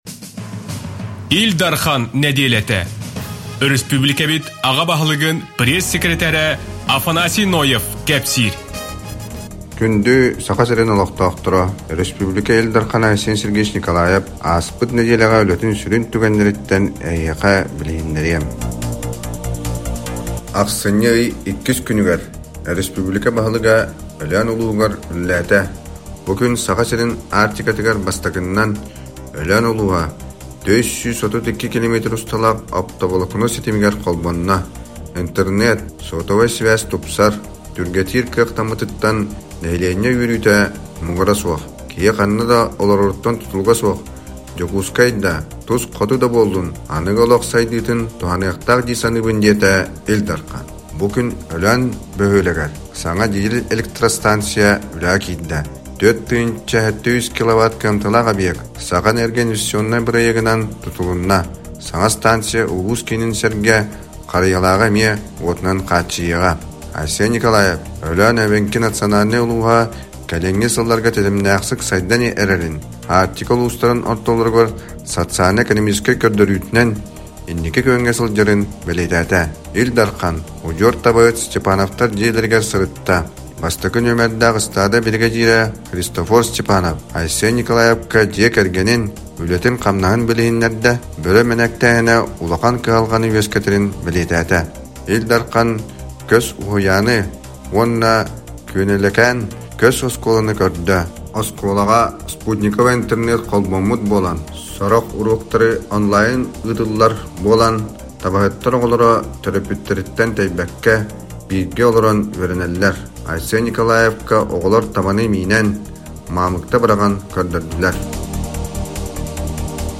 иһитиннэриитэ (аудио)